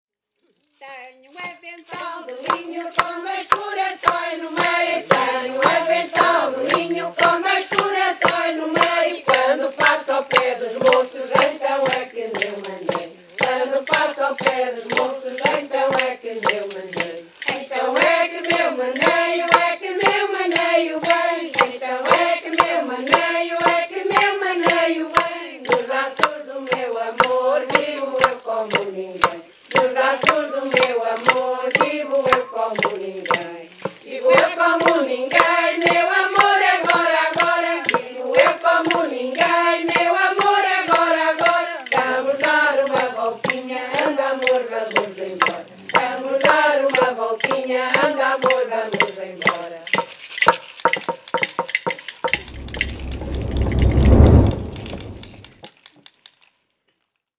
NODAR.00695 – Grupo Etnográfico de Trajes e Cantares do Linho...
Tenho um avental de linho (Versão 2) (Várzea de Calde, Viseu)